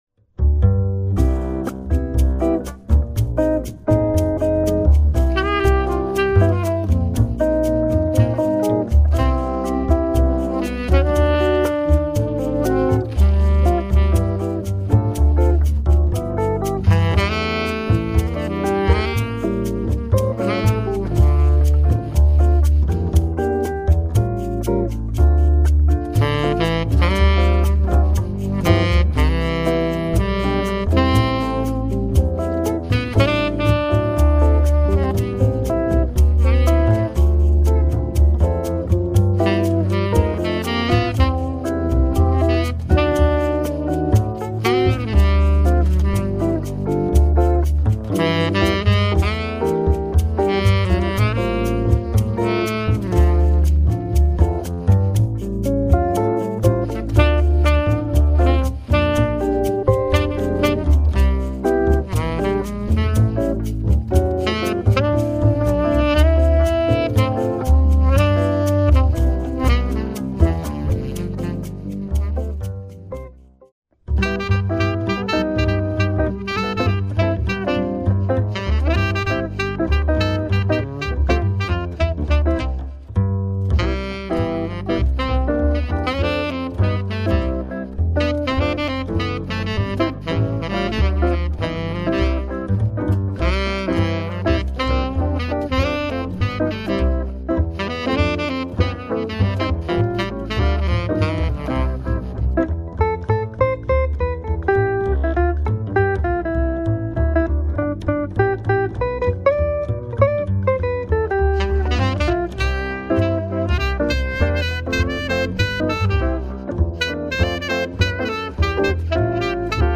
Guitar-Bass-Sax Holiday Jazz Trio